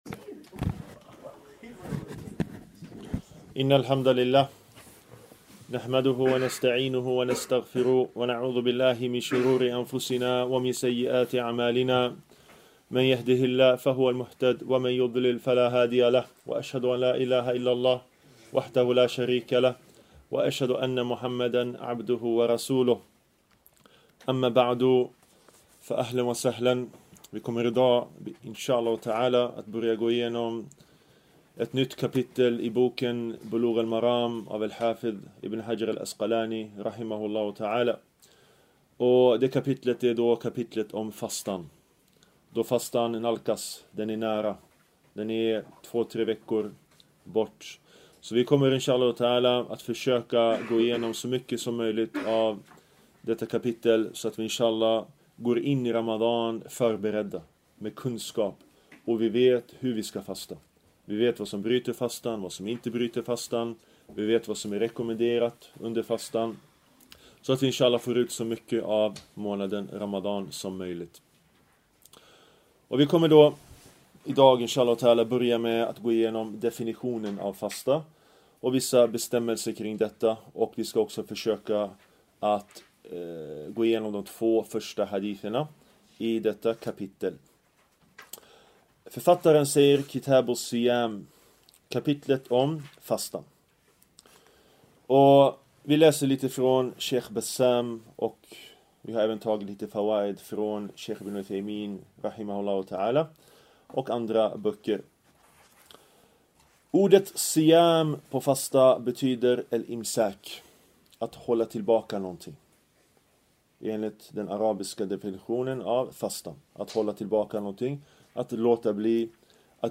En föreläsning av